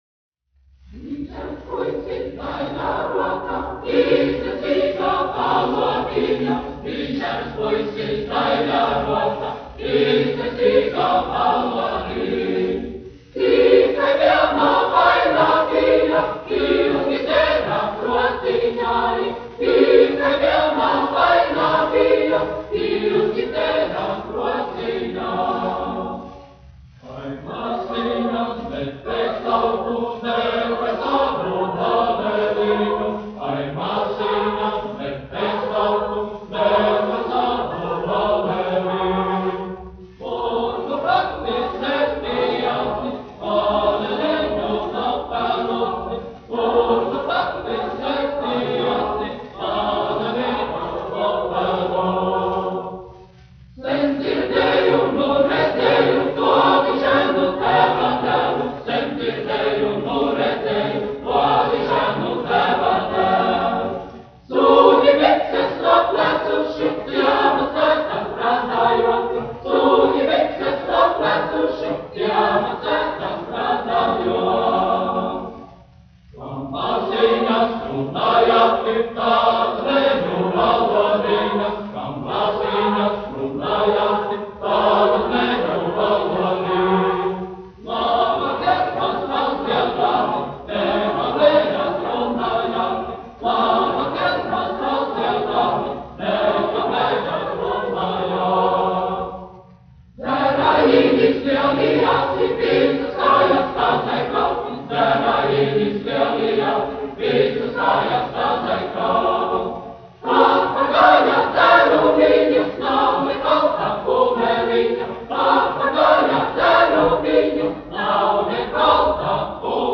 Daugava (koris), izpildītājs
1 skpl. : analogs, 78 apgr/min, mono ; 25 cm
Kori (jauktie)
Latviešu tautasdziesmas
Skaņuplate